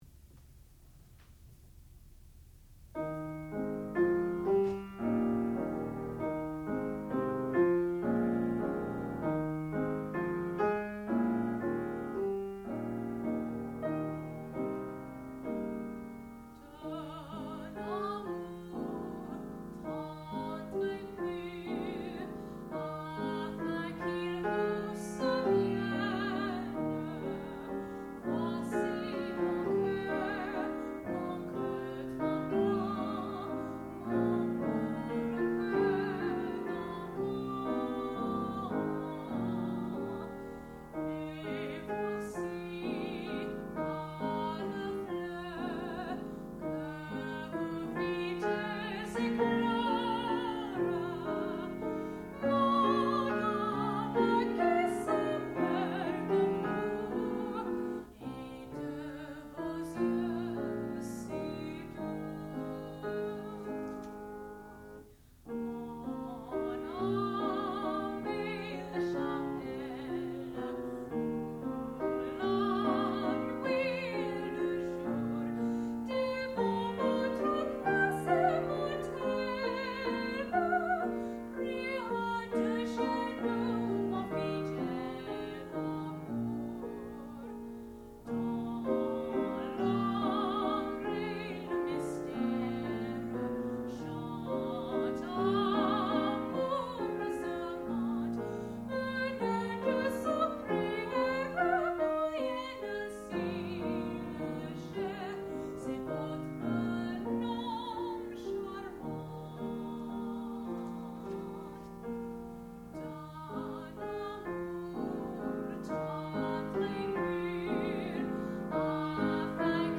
classical music
piano
saxophone
soprano